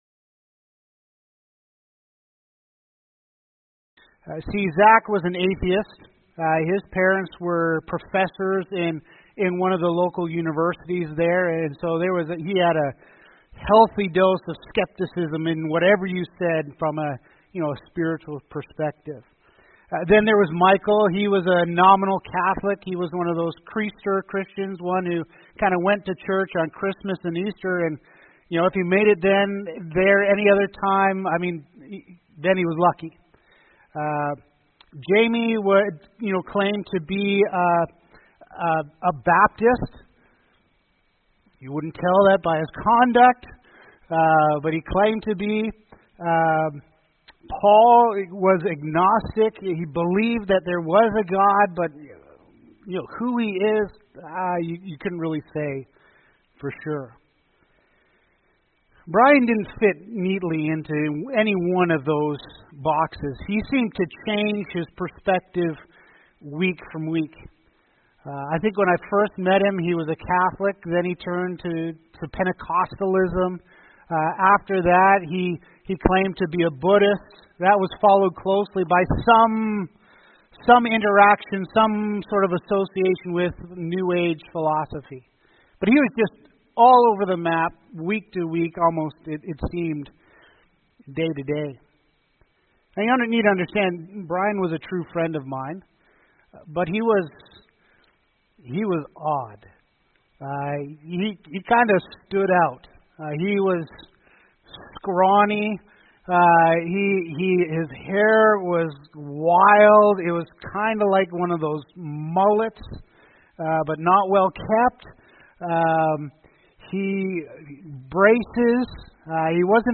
Sunday Sermon Series